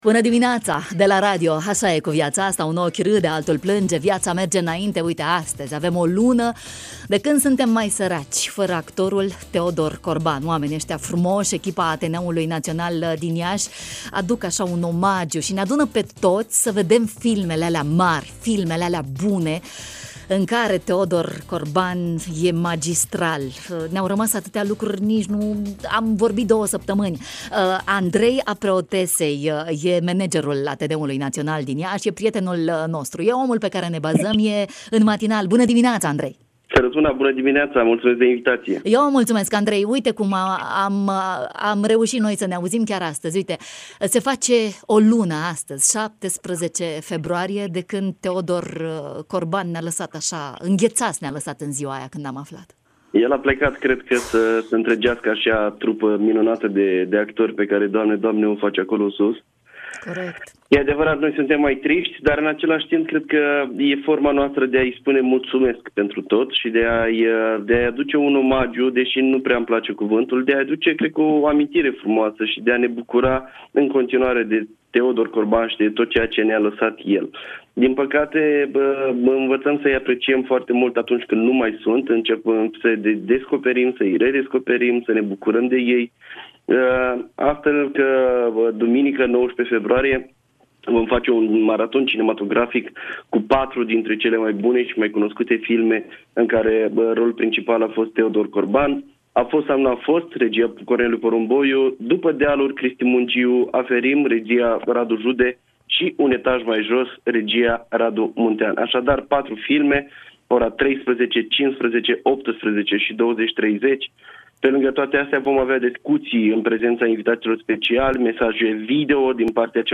În direct la matinalul de la Radio România Iași